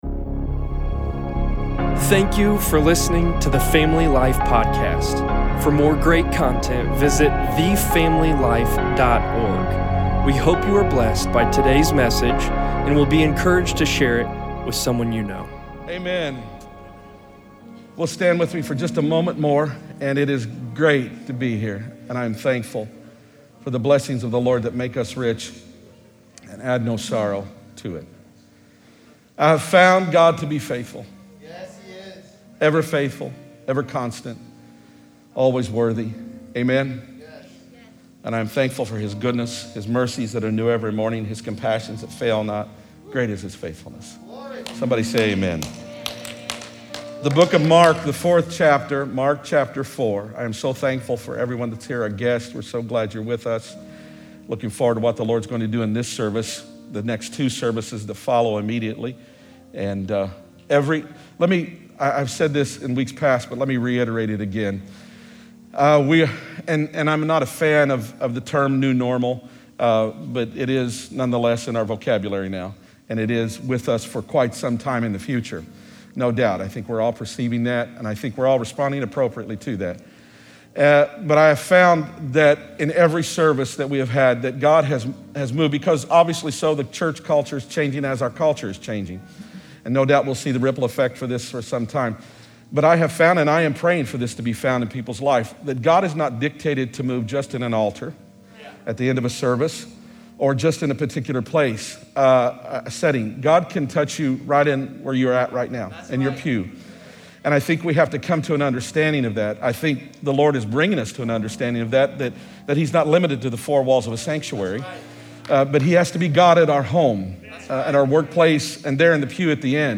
8-23-20_sermon_p.mp3